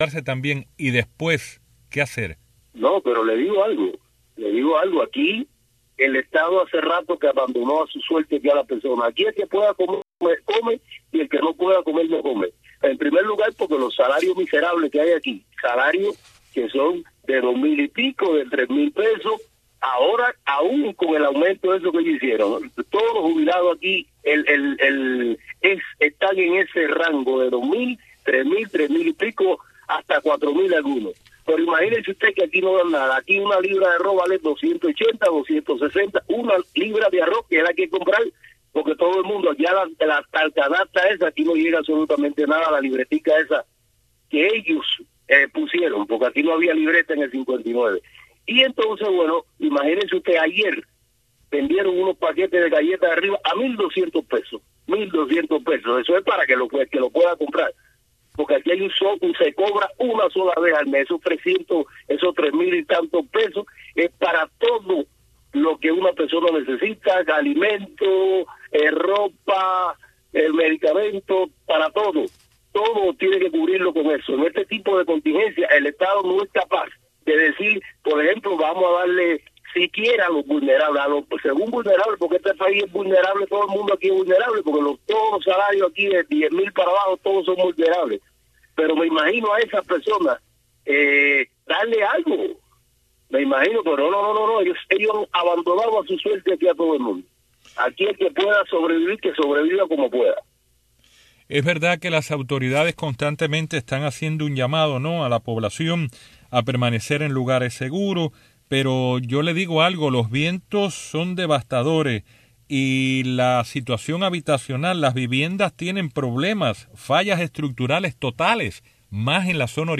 El gobierno cubano desató una oleada represiva tras las protestas del 11 de julio en Cuba. Madres de las víctimas y madres arrestadas en el contexto de las manifestaciones, dan testimonios de violaciones a los derechos humanos y judiciales en Cuba.